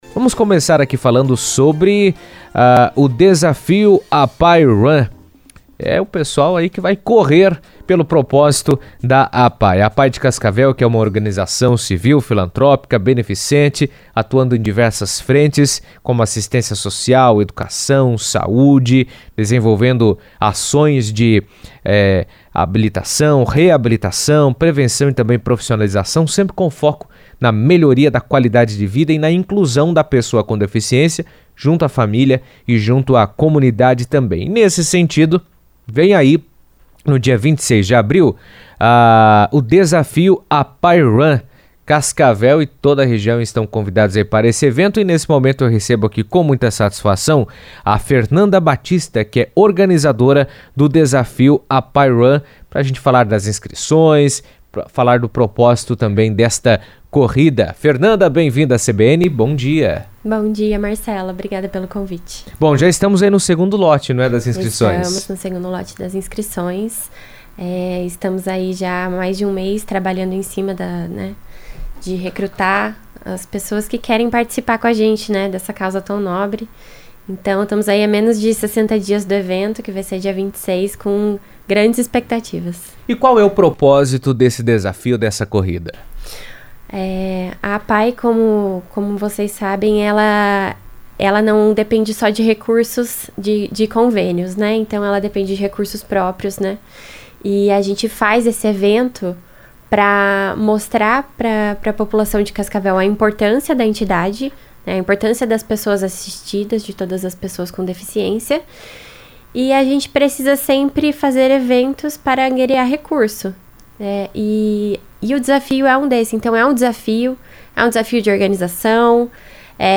As inscrições para o Desafio APAE Run 2026, em Cascavel, já estão abertas. Em entrevista à CBN